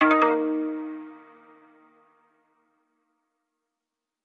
reminder.wav